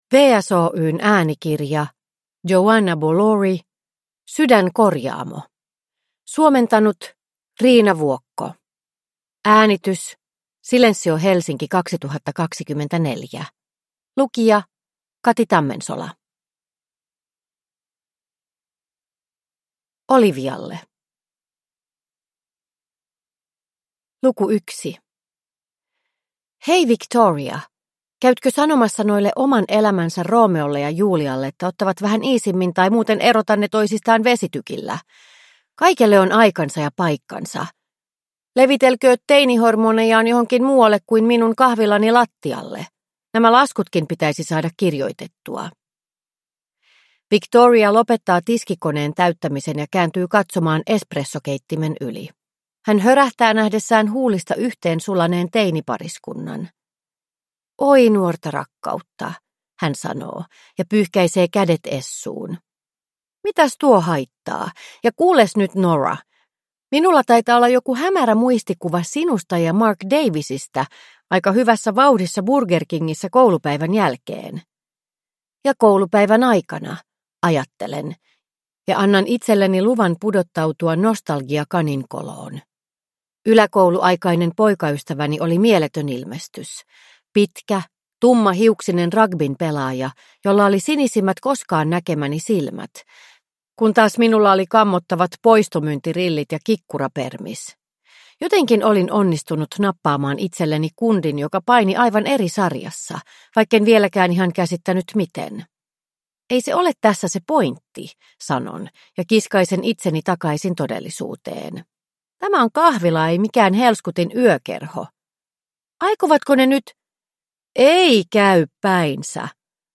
Sydänkorjaamo – Ljudbok